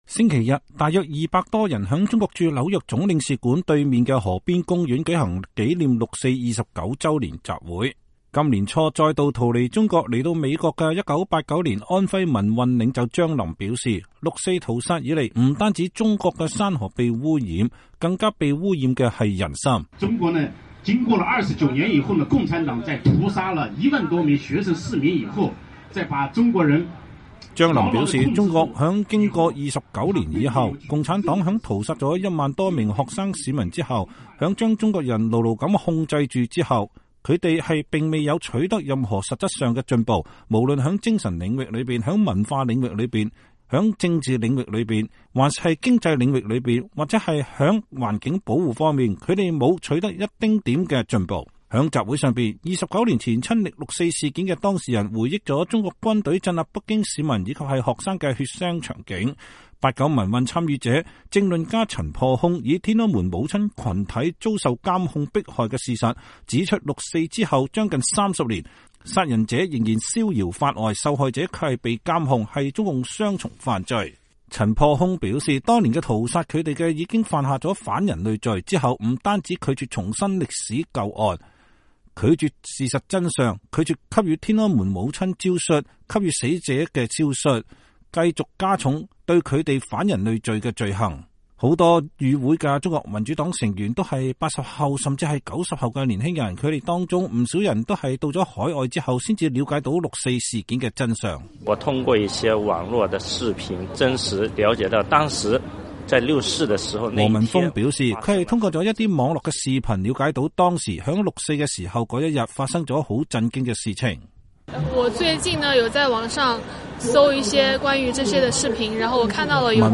紐約舉行紀念六四29週年集會
傍晚，大約200多人聚集在中國駐紐約總領事館對面的河邊公園，在臨時搭起的舞台上，橫幅寫著“勿忘六四，緬懷英烈，憲政民主，未來中國”。